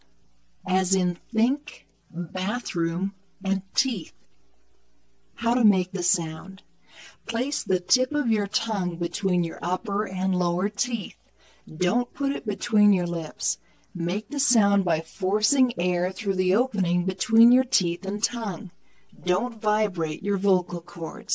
後で紹介するフリーソフトでWaveファイルに変換し、さらに別のフリーソフトで減速させました。
あくまでもフリーソフトなので音質はあまりよくありませんが、聞き取りには十分でしょう。